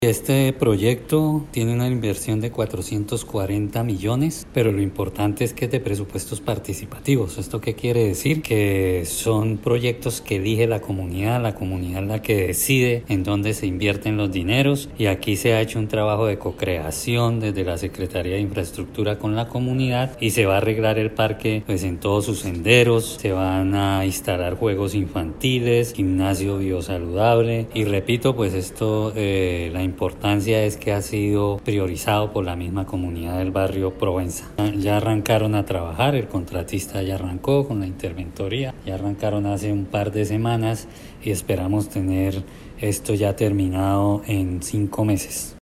Descargue audio: Iván Vargas, secretario de Infraestructura de Bucaramanga